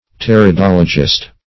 Search Result for " pteridologist" : Wordnet 3.0 NOUN (1) 1. an expert in the study of ferns ; The Collaborative International Dictionary of English v.0.48: Pteridologist \Pter`i*dol"o*gist\, n. One who is versed in pteridology.